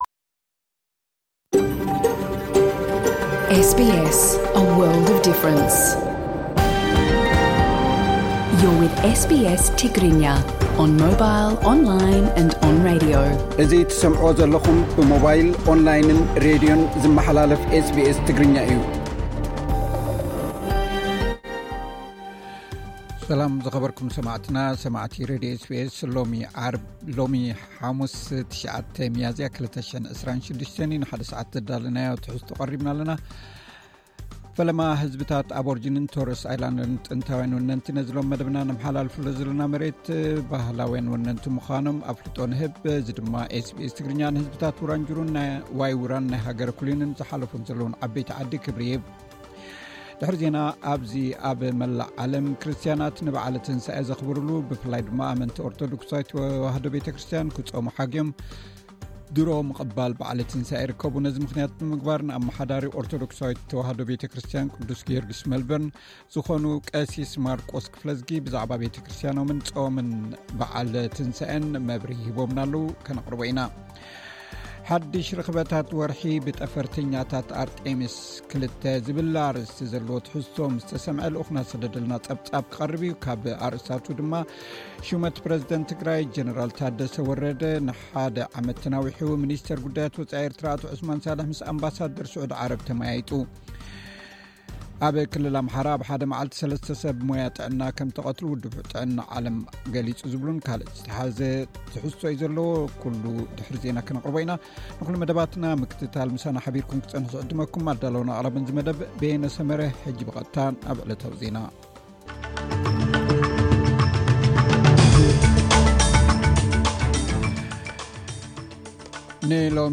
ኣርእስታት ዜና፥